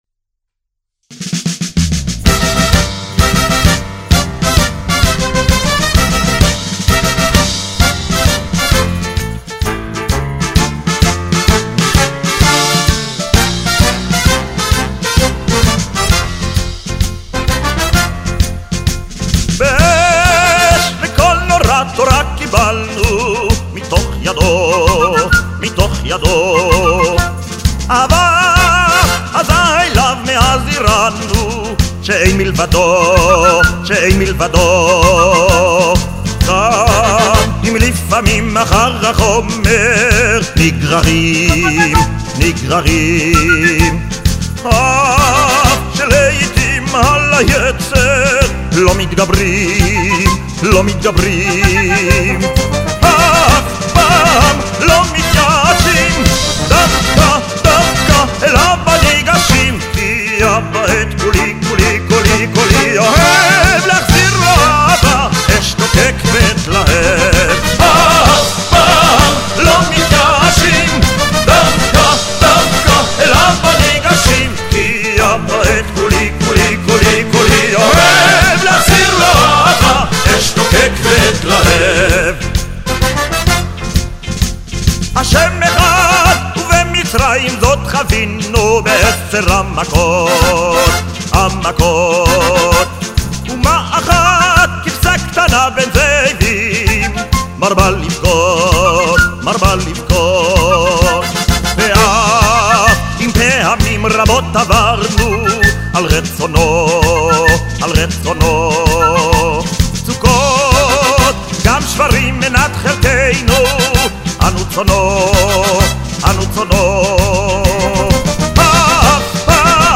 השיר ידוע בתור אריה נפוליטנית